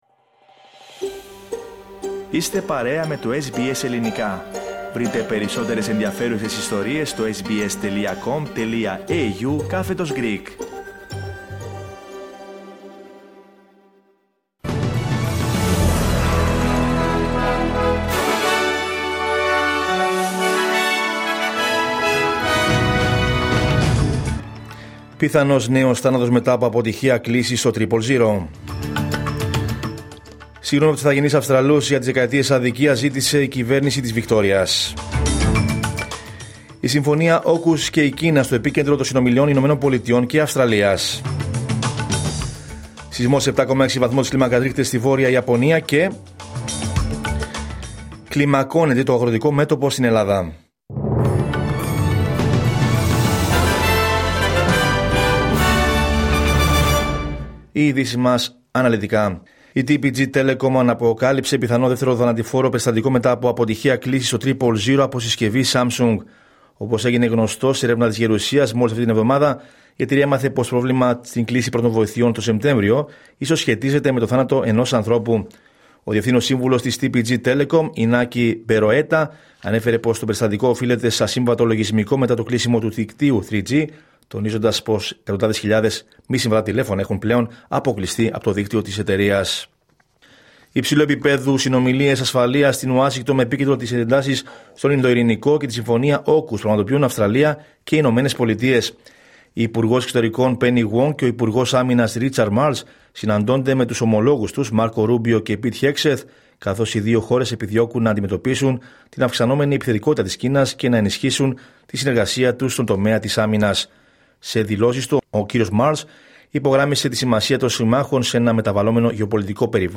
Ειδήσεις: Τρίτη 9 Δεκεμβρίου 2025